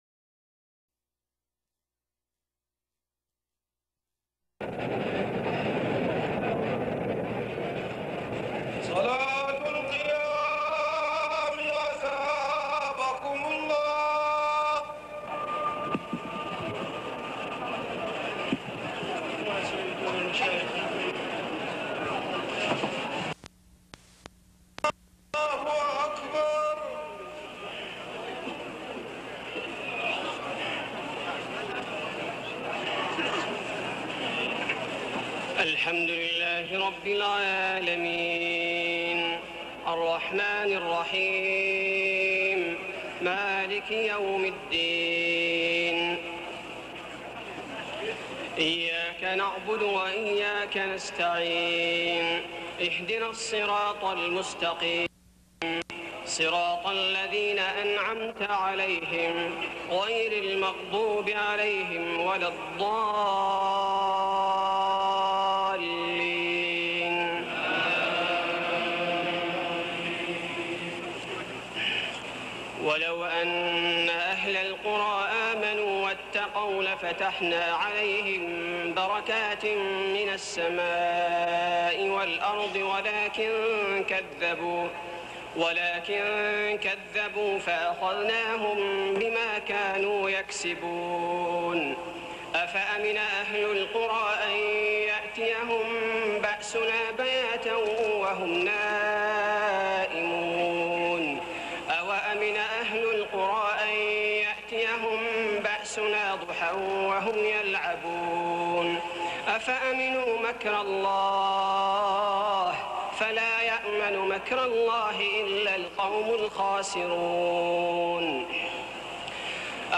تهجد ليلة 29 رمضان 1413هـ من سورة الأعراف (96-186) Tahajjud 29 st night Ramadan 1413H from Surah Al-A’raf > تراويح الحرم المكي عام 1413 🕋 > التراويح - تلاوات الحرمين